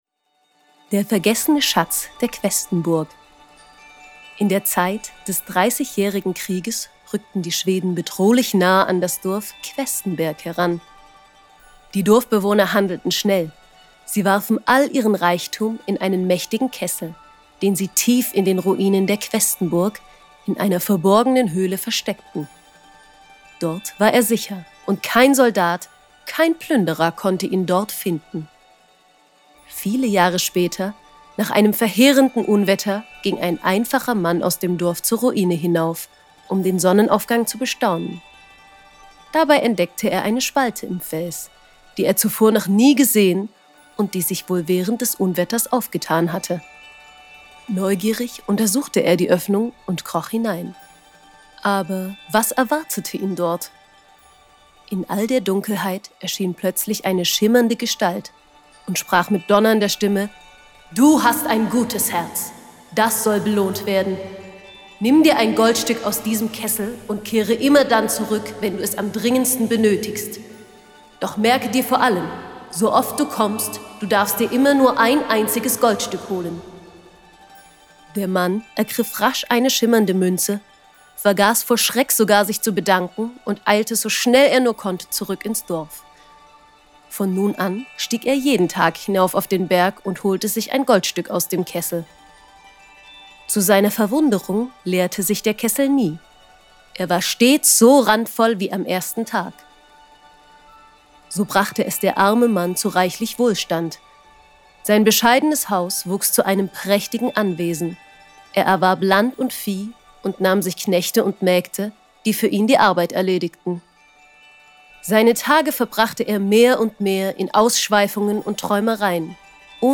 Audioguide-Suedharz-Maerchen-4-Der-vergessene-Schatz-der-Questenburg.mp3